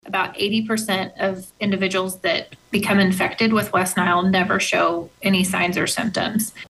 CLICK HERE to listen to commentary from State Epidemiologist, Jolianne Stone.